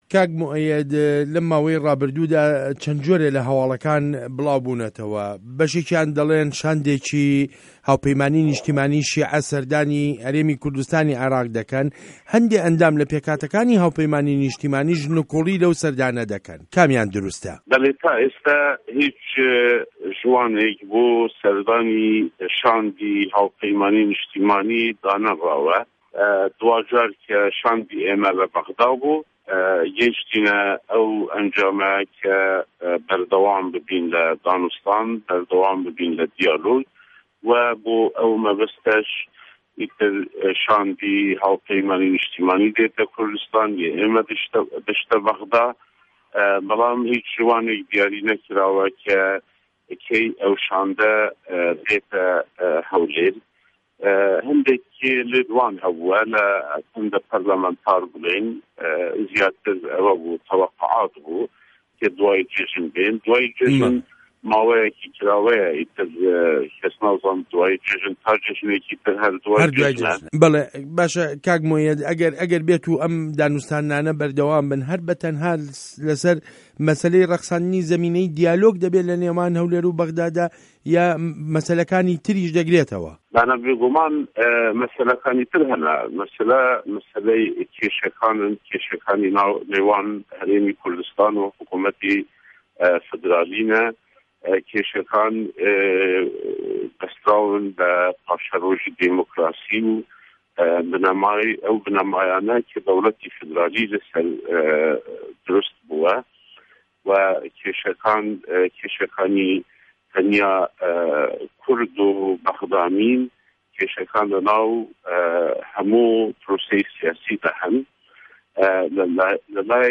وتووێژ له‌گه‌ڵ موئه‌یه‌د ته‌یب تاهیر